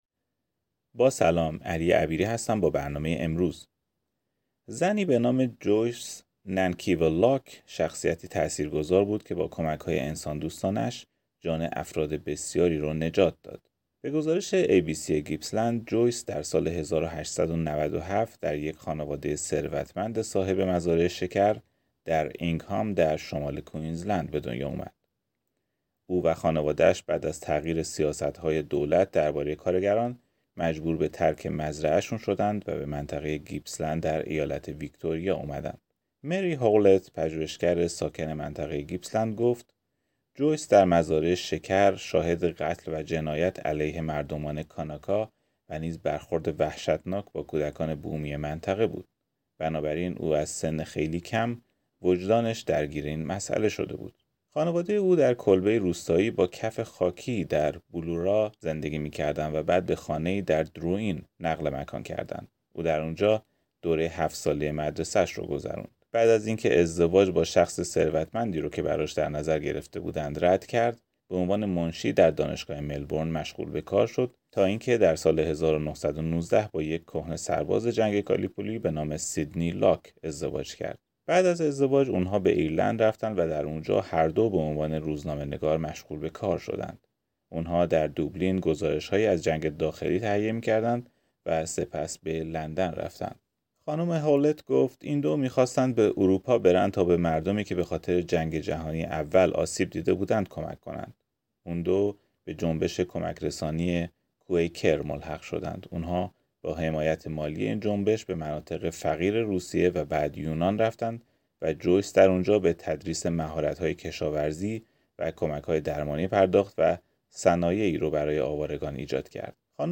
این پادکست ترجمه گزارشی است که ای بی سی گیپسلند آن را تهیه کرده است.